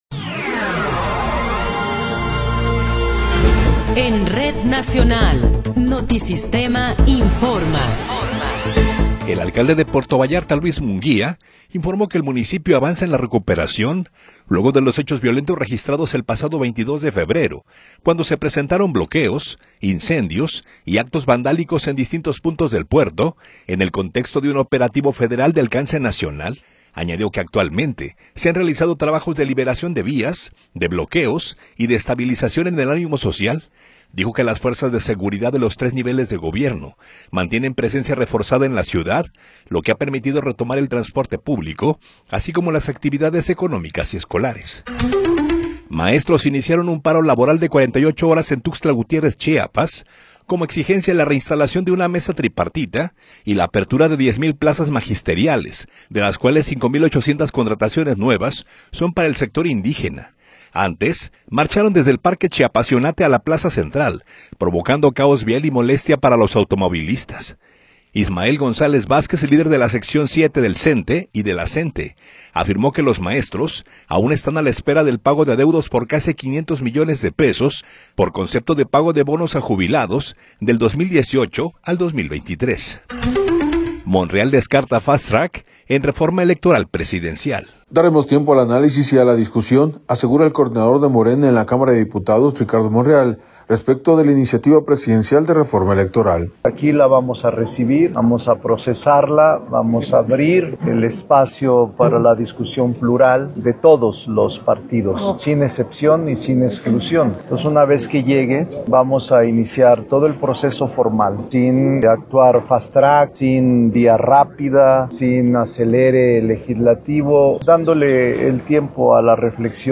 Noticiero 19 hrs. – 26 de Febrero de 2026
Resumen informativo Notisistema, la mejor y más completa información cada hora en la hora.